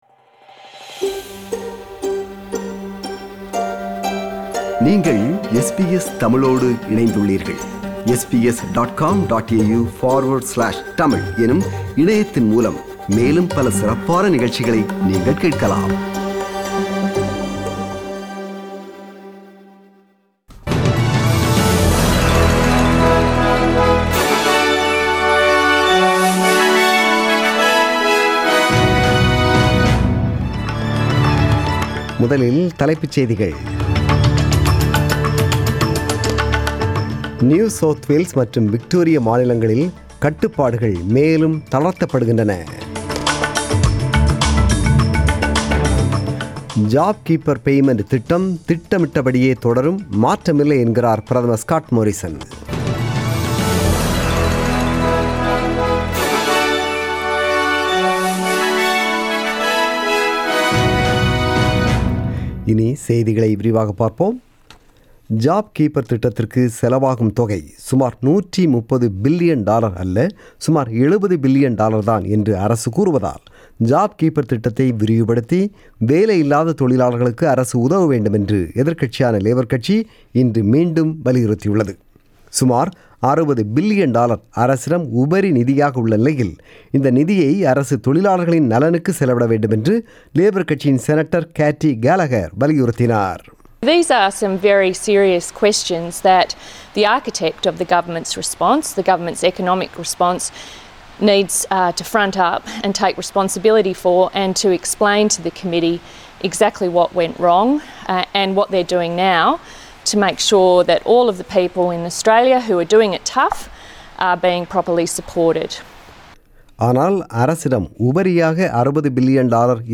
The news bulletin was broadcasted on 24 May 2020 (Sunday) at 8pm.